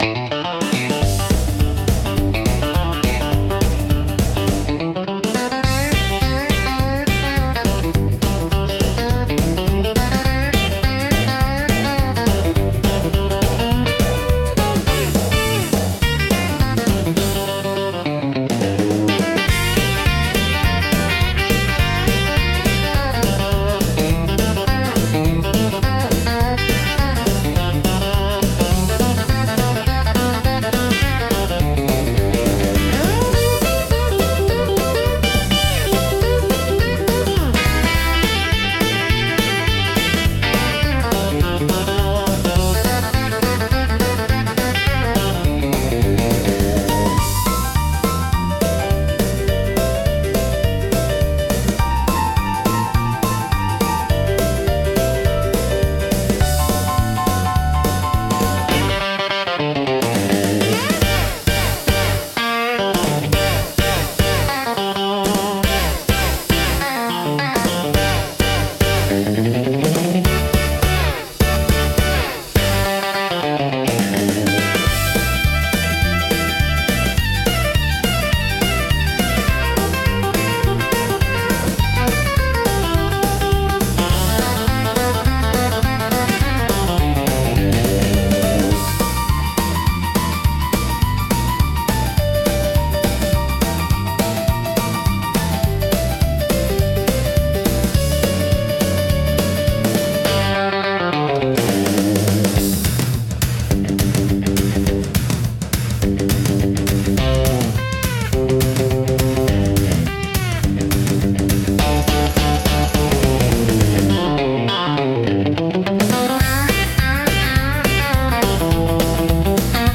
懐かしさと若々しいエネルギーが共存し、楽しい空気づくりに効果的なジャンルです。